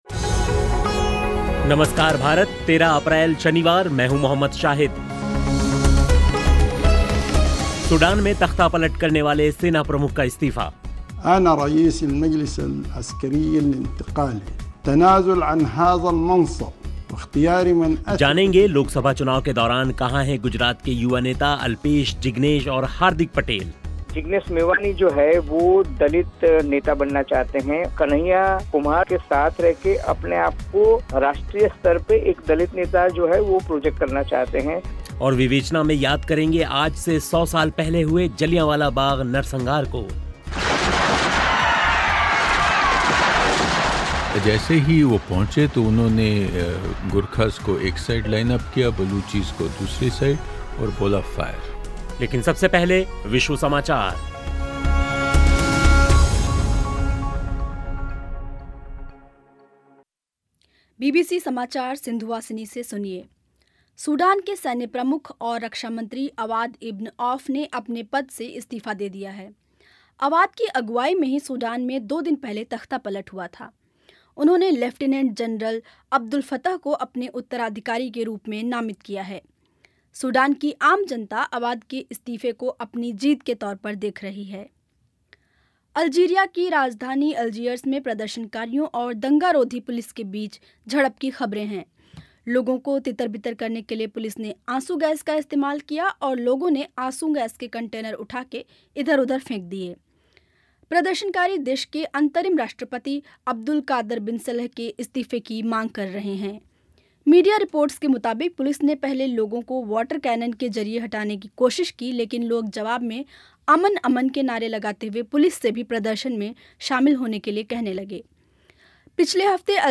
जानेंगे लोकसभा चुनाव के दौरान कहां हैं गुजरात के युवा नेता अल्पेश, जिग्नेश और हार्दिक पटेल. और विवेचना में याद करेंगे आज से 100 साल पहले हुए जलियांवाला बाग़ नरसंहार को. लेकिन सबसे पहले विश्व समाचार सुनिए.